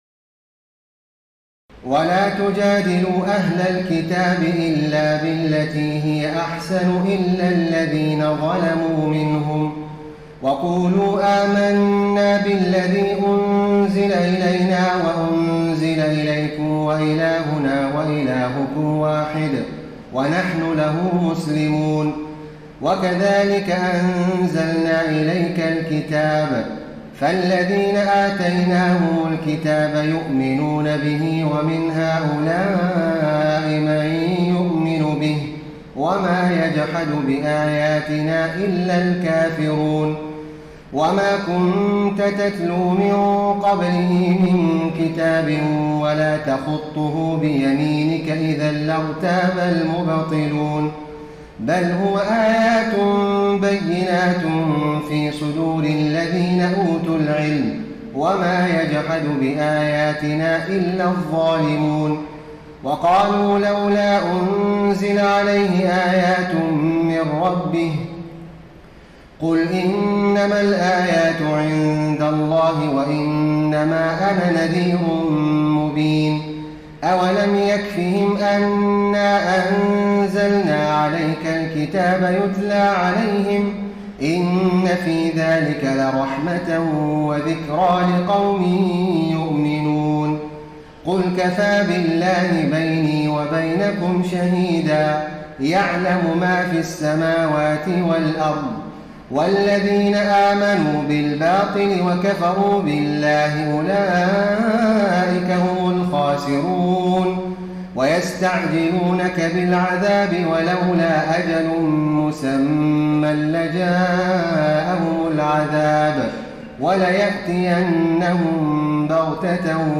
تراويح الليلة العشرون رمضان 1433هـ من سور العنكبوت (46-69) و الروم و لقمان (1-21) Taraweeh 20 st night Ramadan 1433H from Surah Al-Ankaboot and Ar-Room and Luqman > تراويح الحرم النبوي عام 1433 🕌 > التراويح - تلاوات الحرمين